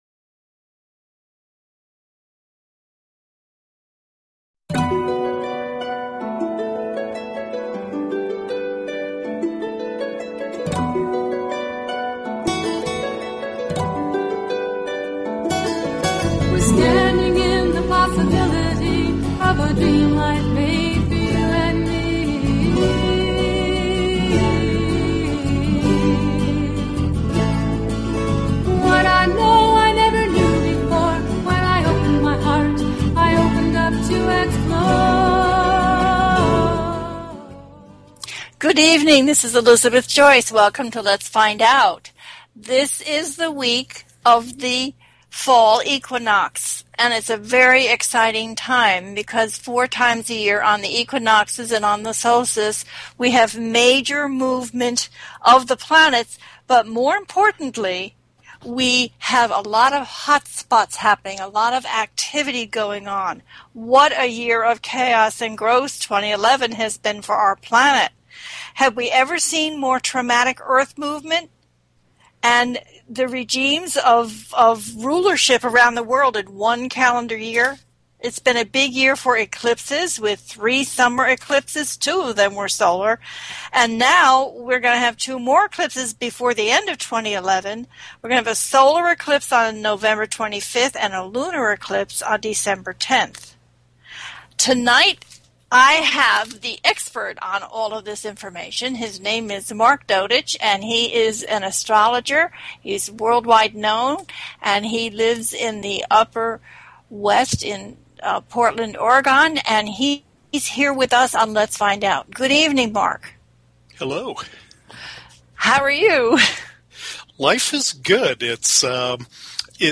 Talk Show Episode, Audio Podcast
THIS IS A CALL IN SHOW, so please get your questions ready and give us a call.